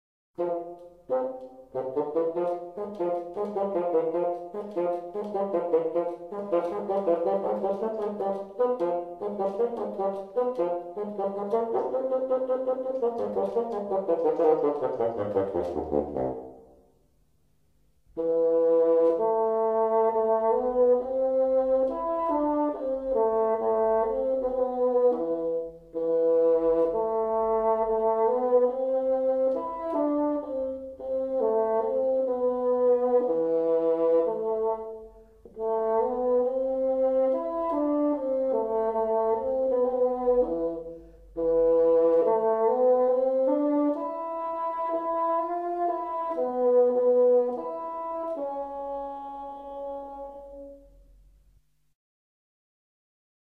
16 Fagot.mp3